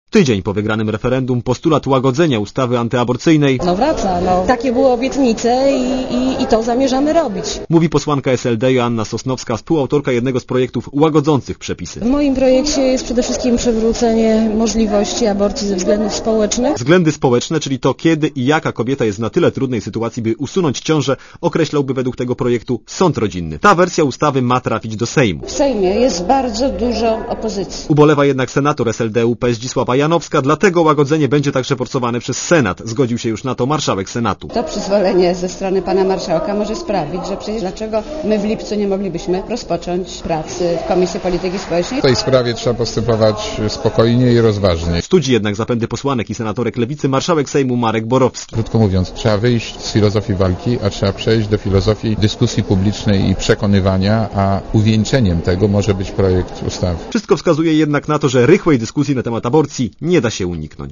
Relacja reportera Radia Zet (240Kb)